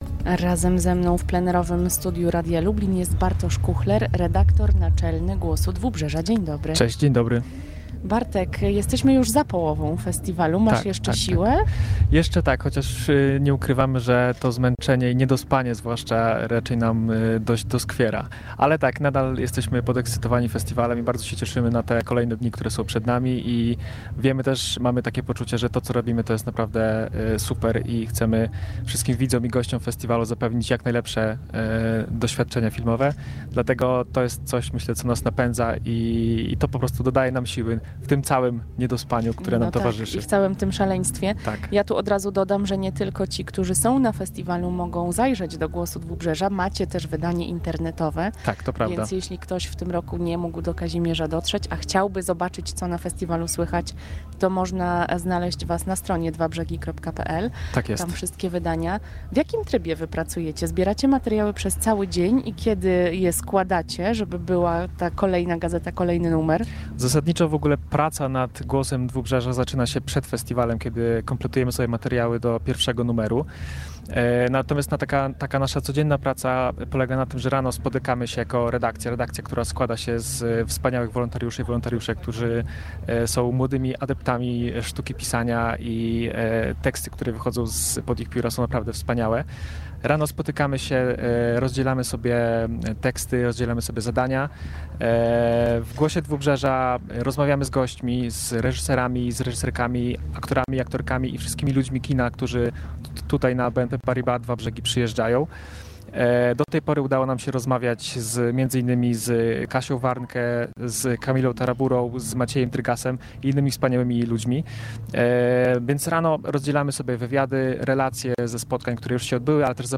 Relacje z 19. edycji BNP Paribas Dwa Brzegi w Kazimierzu Dolnym na antenie Radia Lublin w Drugiej Połowie Dnia między 12:00 a 15:00.